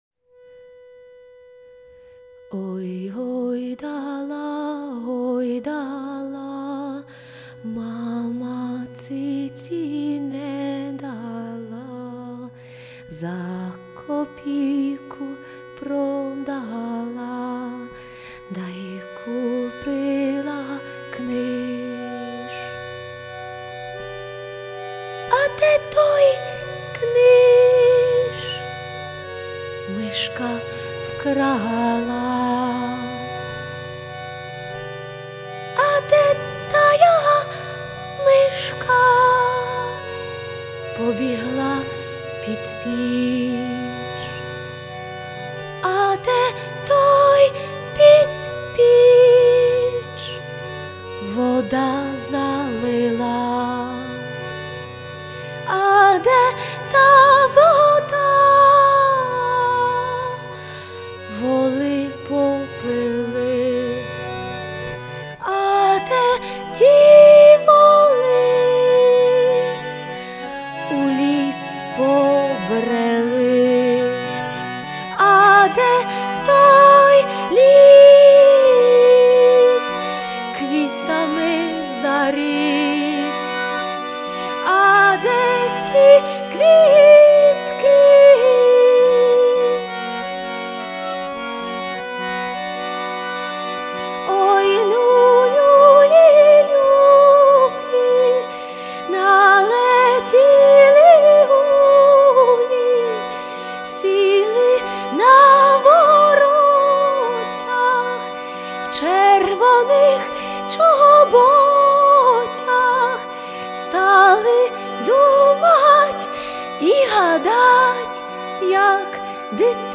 Она не совсем в тему, но играет очень чисто.